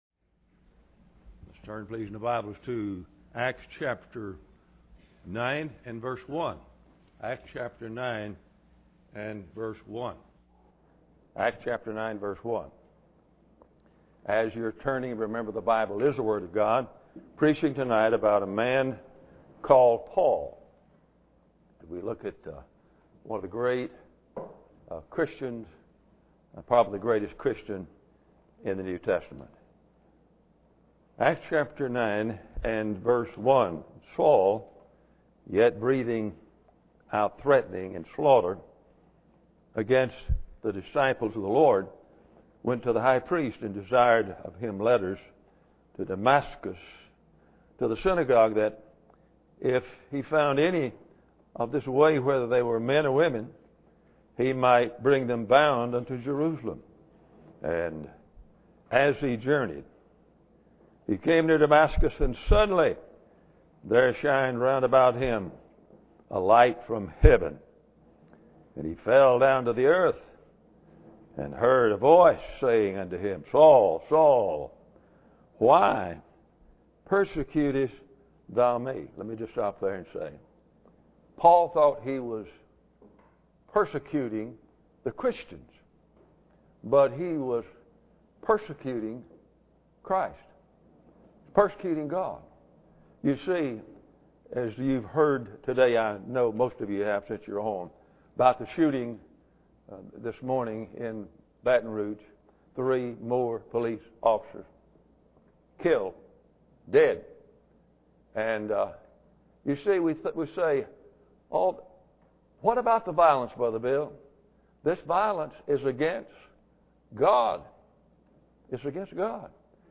Acts 9:1-11 Service Type: Sunday Evening Bible Text